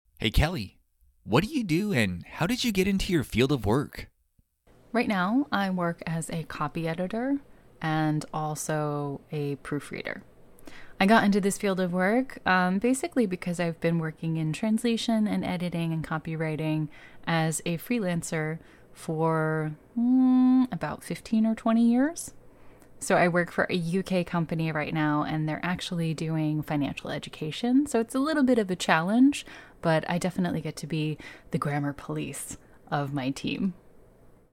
2. Conversation